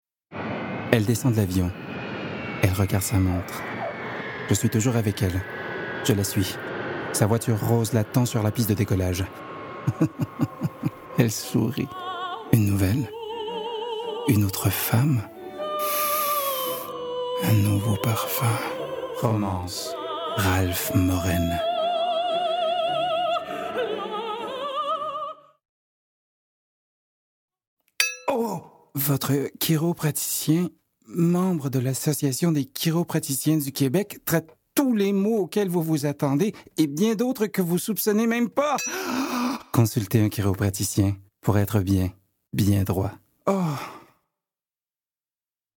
Démo voix - FR